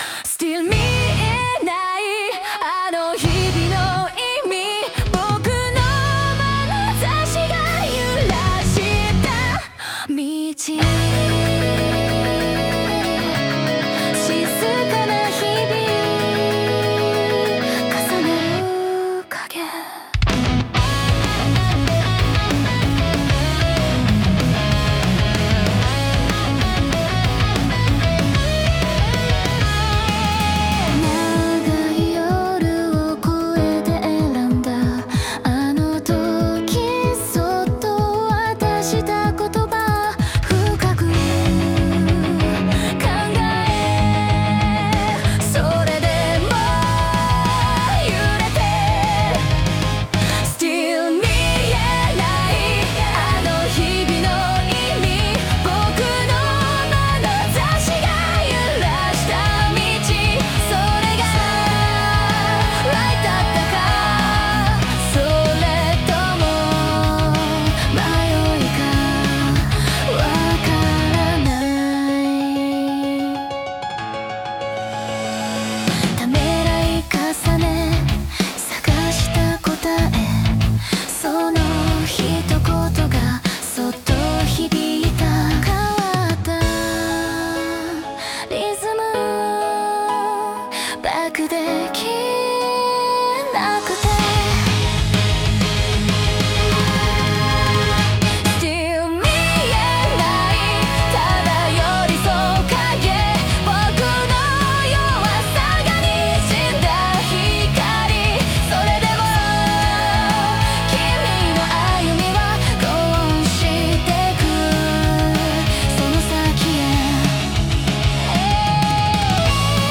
女性ボーカル
イメージ：J-ROCK,女性ボーカル,かっこいい,シューゲイザー